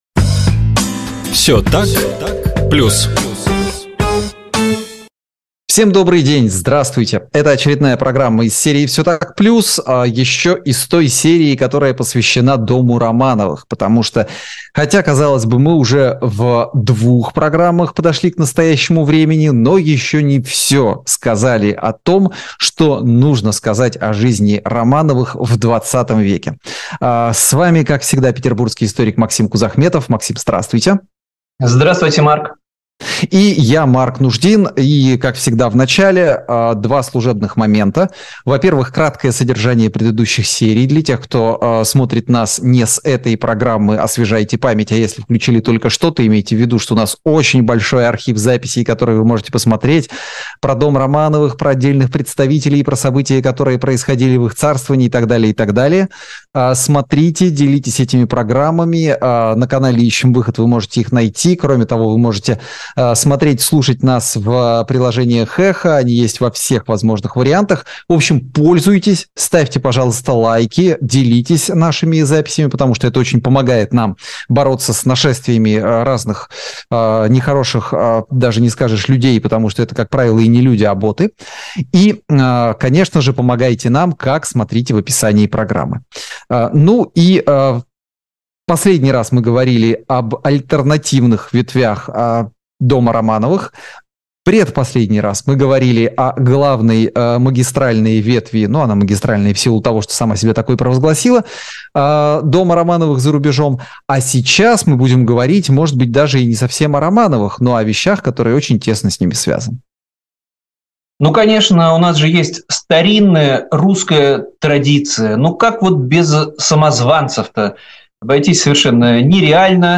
журналист
историк